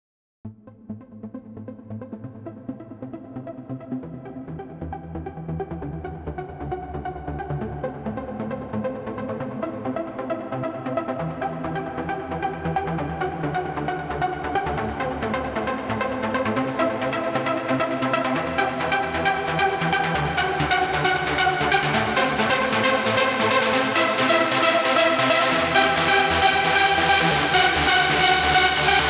Trance песочница (крутим суперпилы на всём подряд)
Нравится она мне, не совсем банальная.